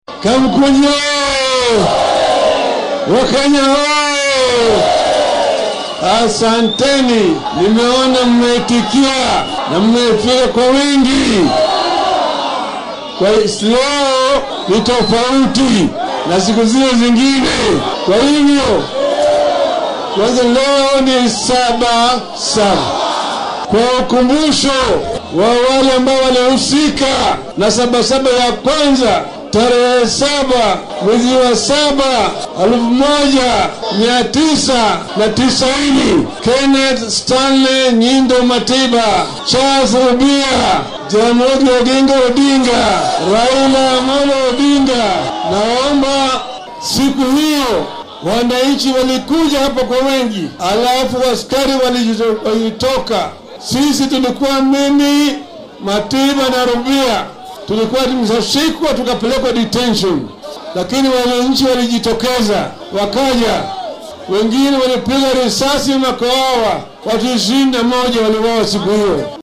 Hoggaamiyaasha isbeheysiga mucaaradka ee Azimio oo uu hor kacaya Raila Odinga ayaa dadweynaha kula hadlay garoonka Kamkunji waxaana Mr. Odinga hadalladiisa ka mid ahaa.